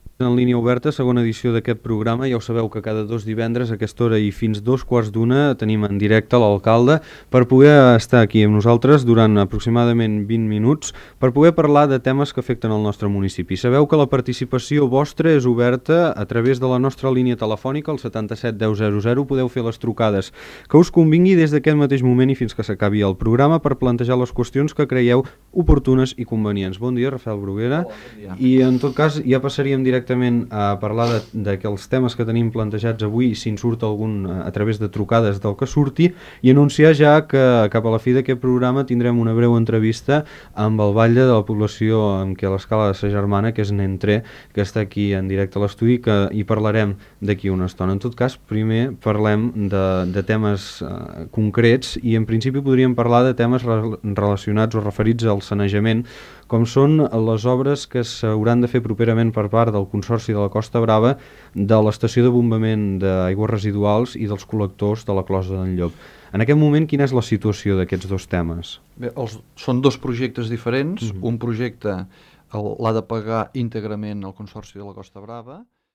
Entrevista a l'alcalde Rafel Bruguera.
Informatiu